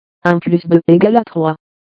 Französische Stimmen
Lernout & Hauspie® TTS3000 TTS engine – French